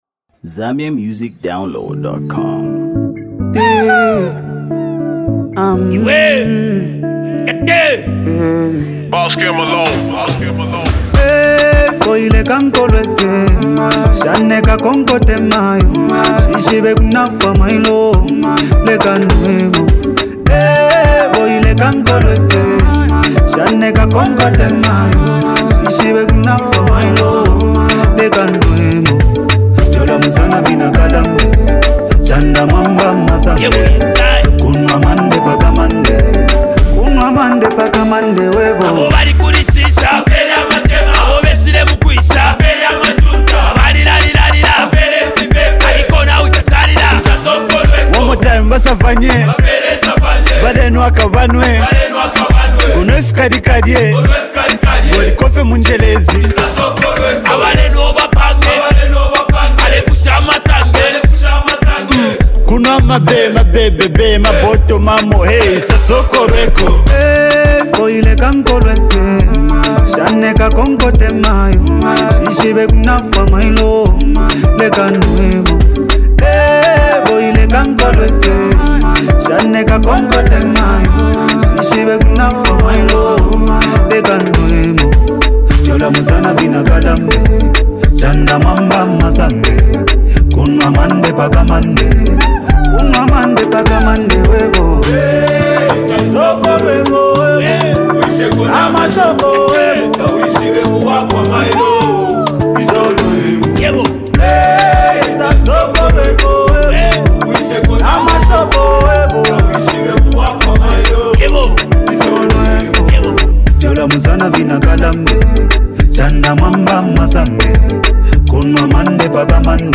Most happening music duo in the country
male vocalist